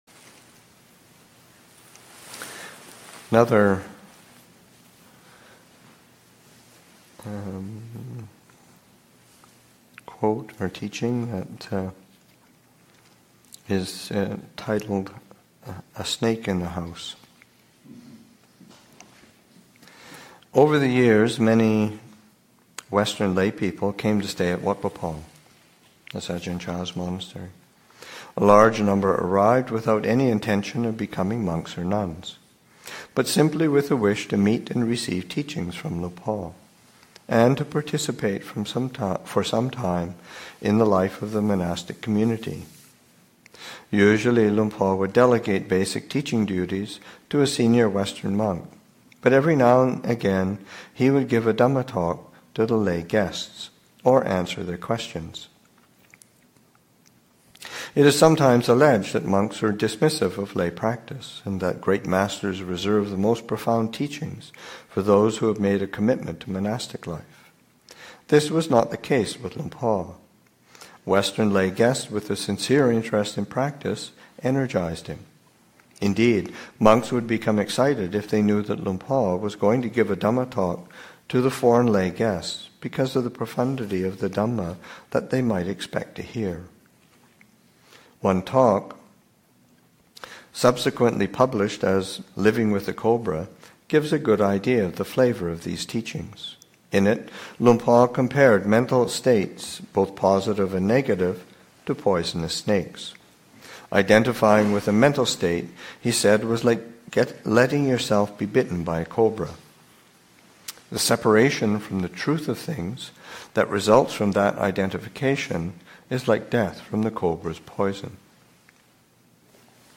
Reading